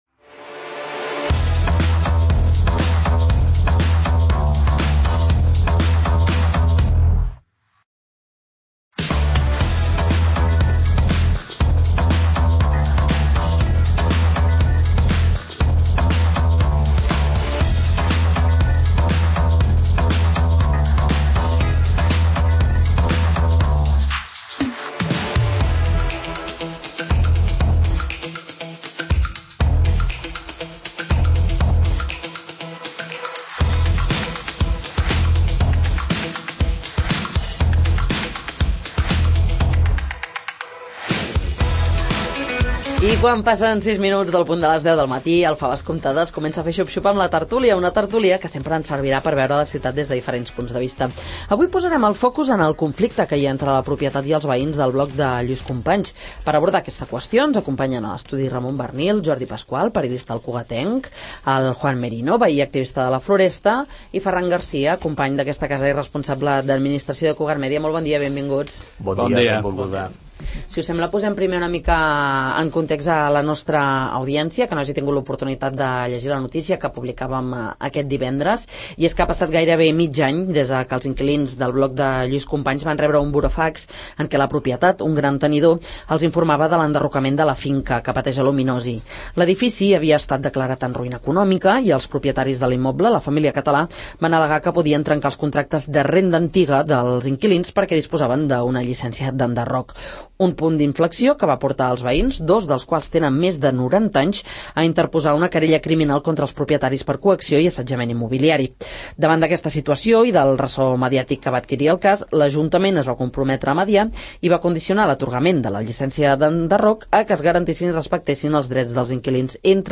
Quin paper ha de tenir l'Ajuntament en el conflicte de l'edifici de Llu�s Companys? En parlem a la tert�lia del 'Faves comptades'